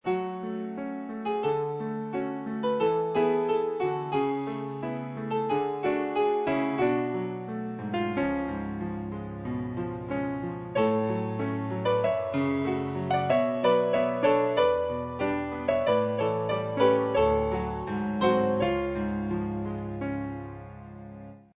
01 piano .mp3